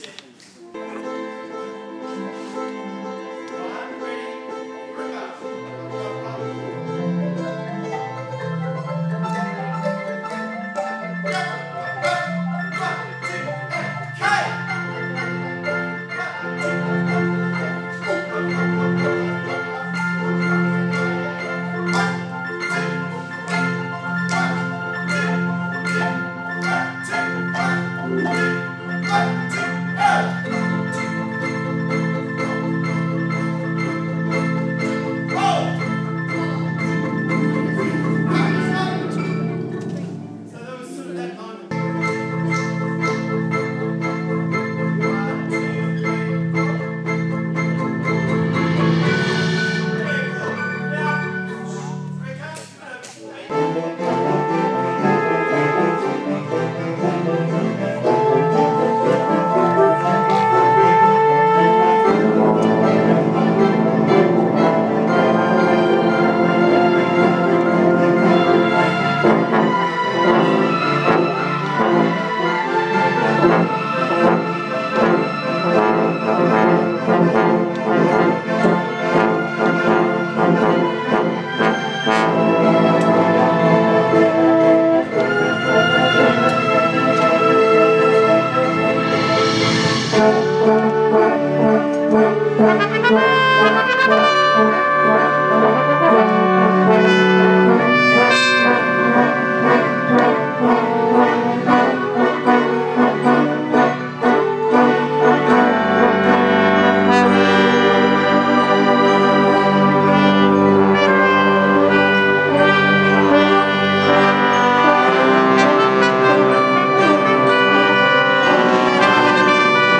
"Passion" in rehearsal
My new piece for symphonic winds in rehearsal. May sound v trombone centric as I was playing at the end!